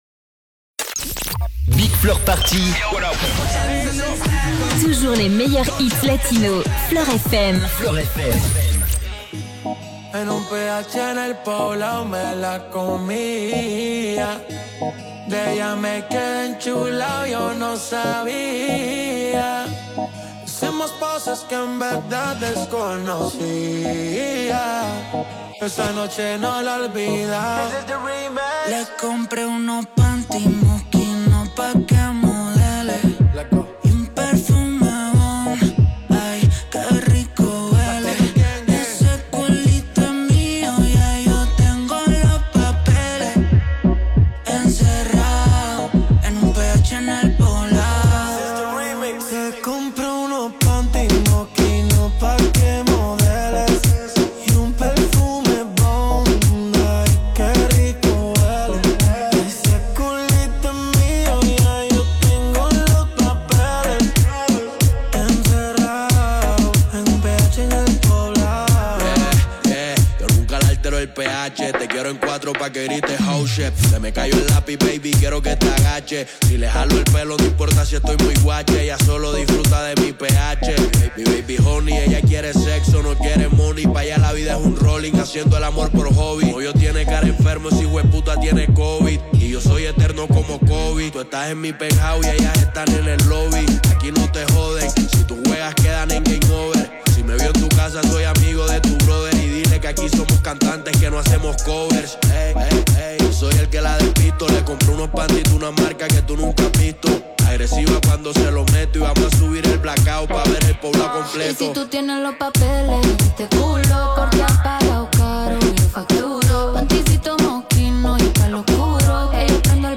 Le meilleur du son club en Alsace